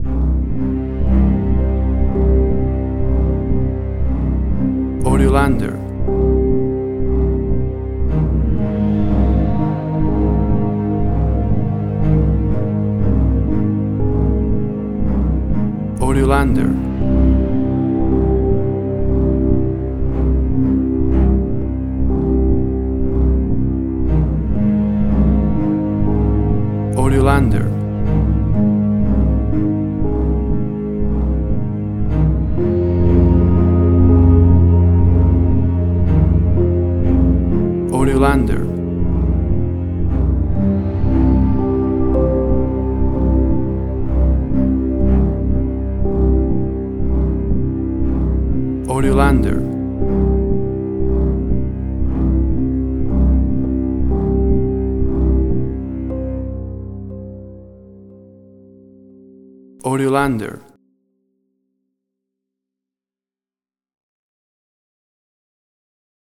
Mystery orchestral music, smooth but intense.
Tempo (BPM): 65